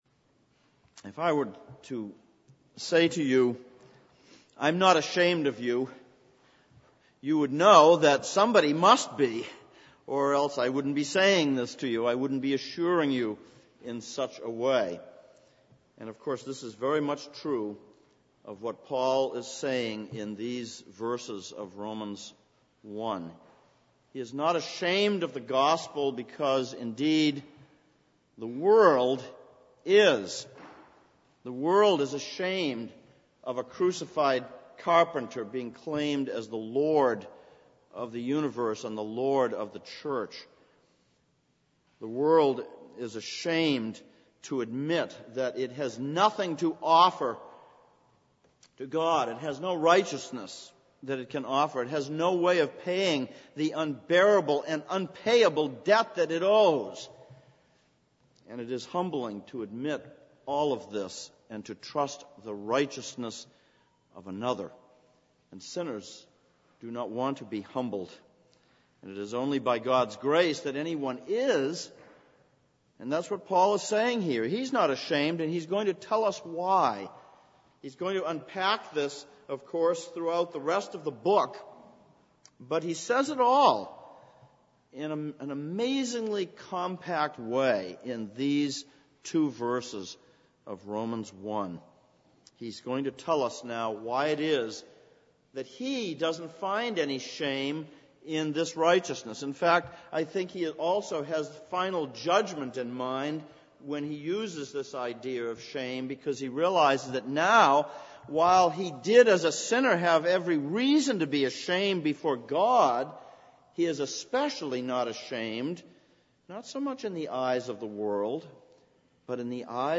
Exposition of Romans Passage: Romans 1:8-17 Service Type: Sunday Morning « 02.